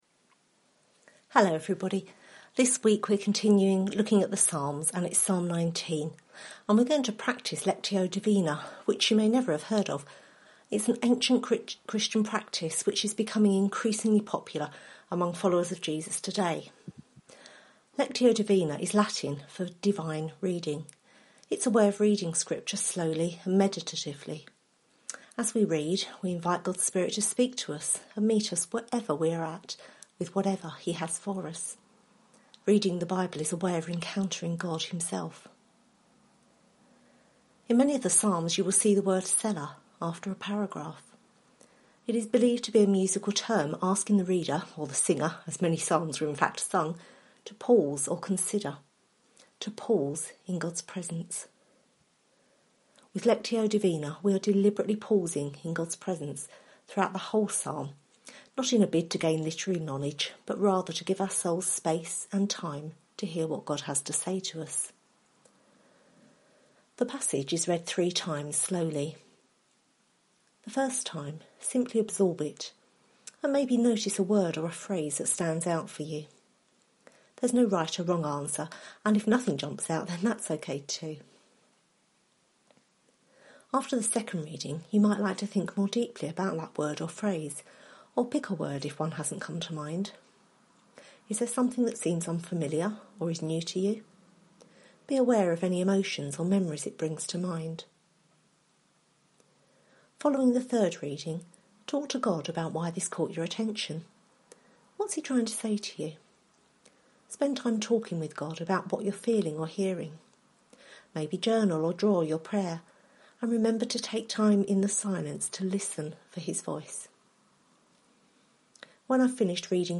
Lectio Divina
Series: Psalms Service Type: Sunday Morning